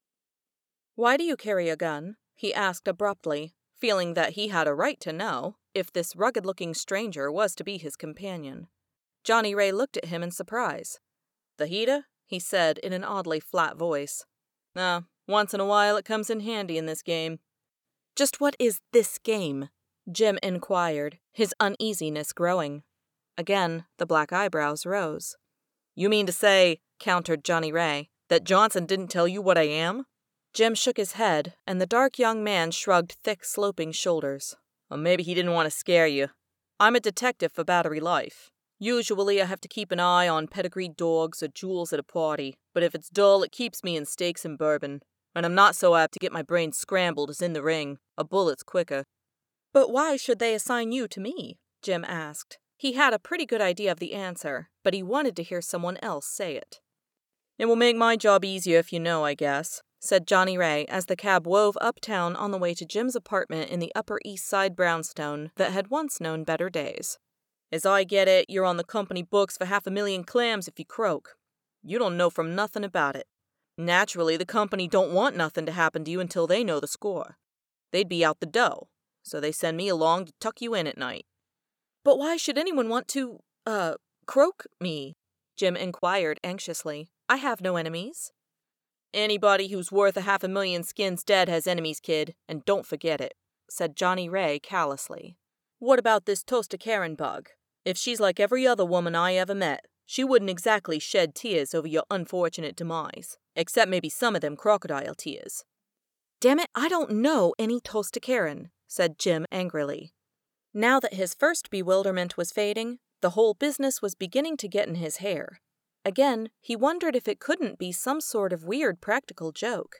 THIS IS AN AUDIOBOOK